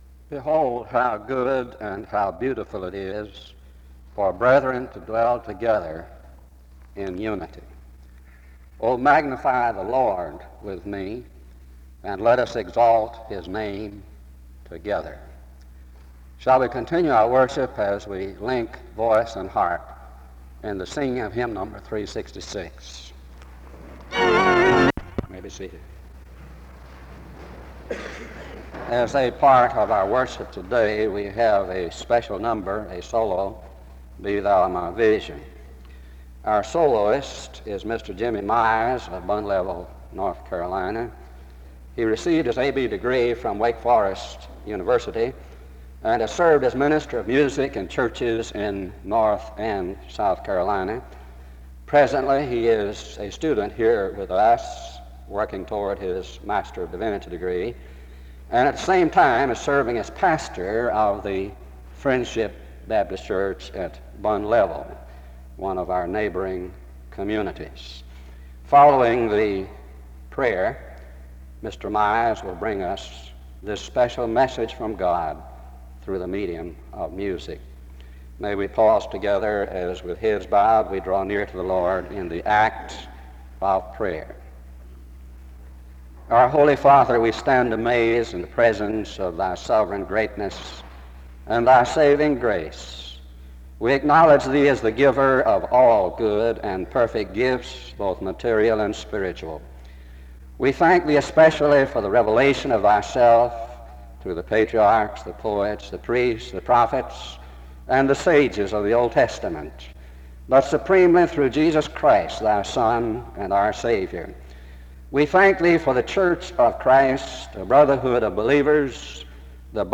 After which, a prayer is offered (1:24-4:17).
This chapel is distorted from 0:00-5:15.